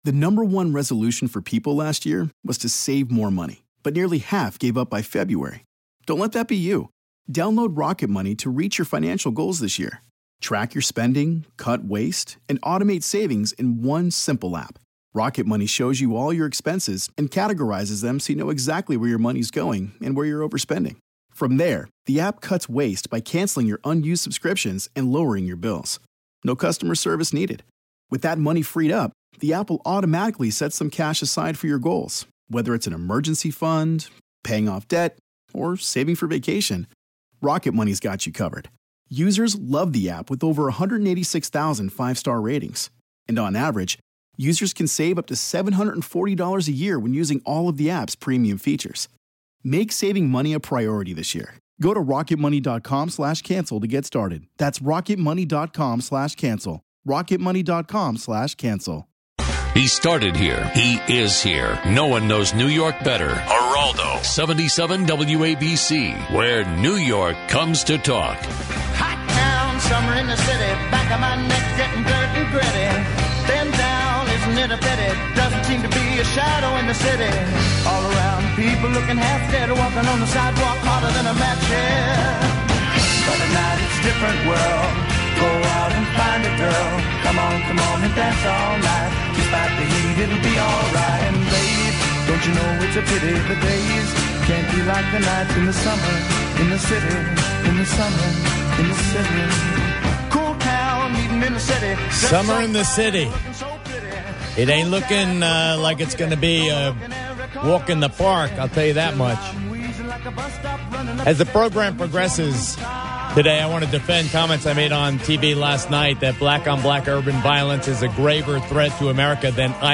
Geraldo Rivera talks about issues New Yorkers care about.